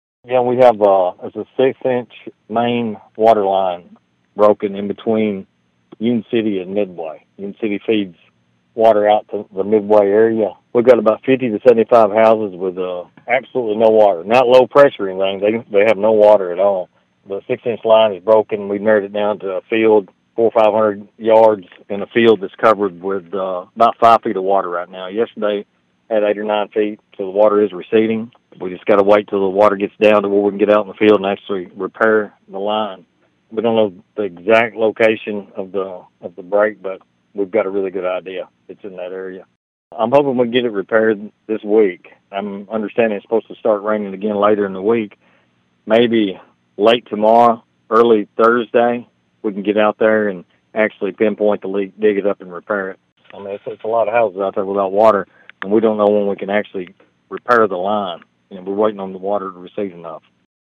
City Manager Johnny McTurner explained the issue facing the Midway community.(AUDIO)